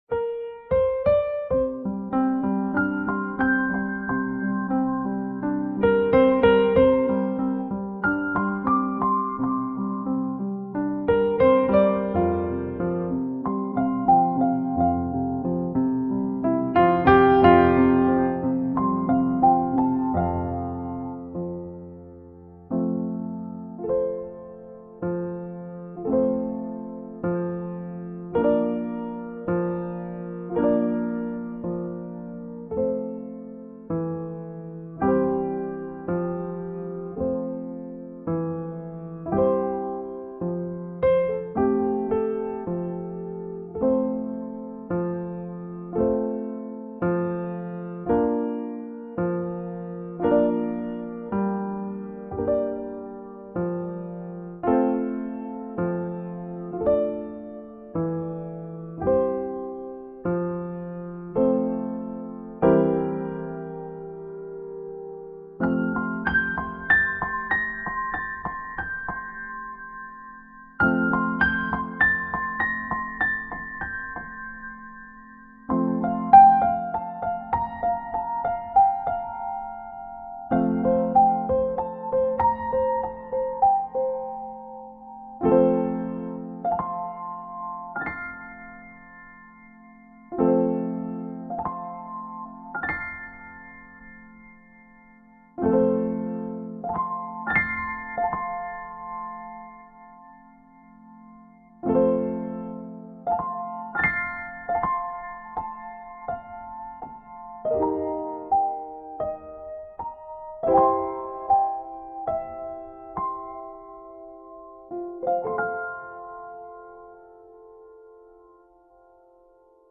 我自己又长了一遍， 把人声降为零倒腾的